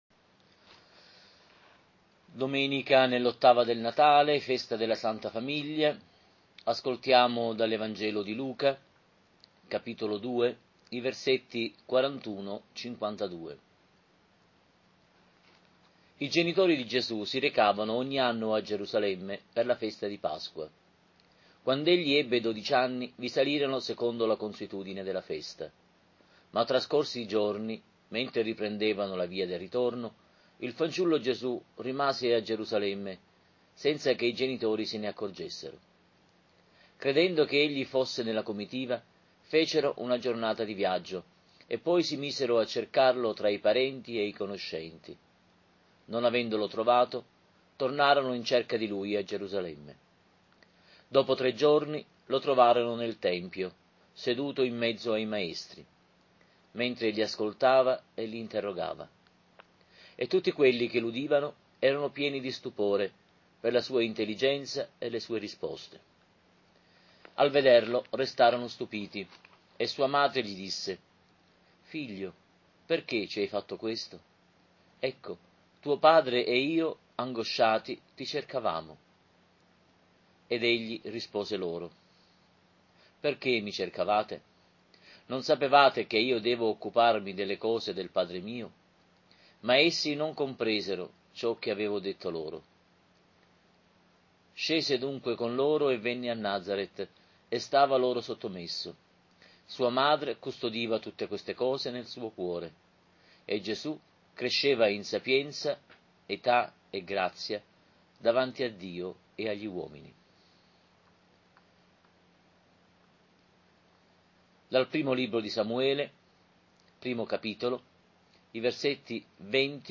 Lectio divina DOMENICA NELL’OTTAVA DEL NATALE, Festa della SANTA FAMIGLIA, ANNO C